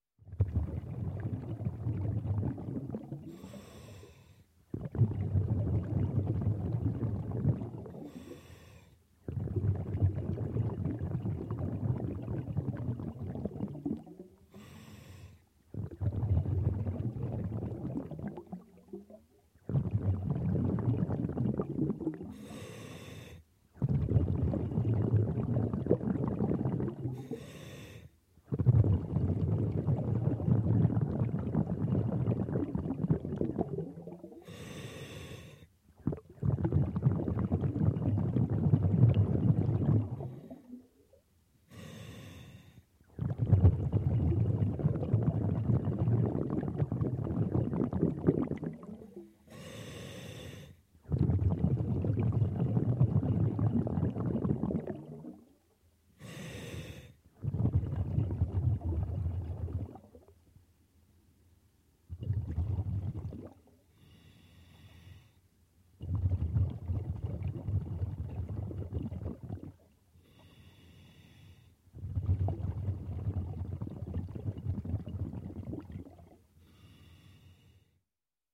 Звуки дайвинга
Звук дыхания аквалангиста при погружении в морские или океанские глубины